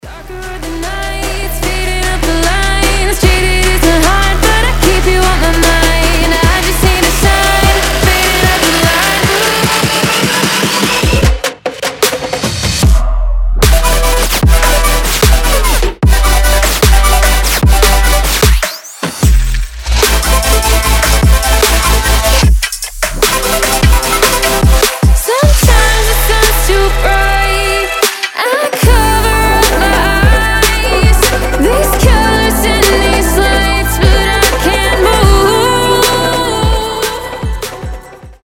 громкие
мощные
женский голос
Electronic
космические
future bass
звонкие
Отличный future bass/ trap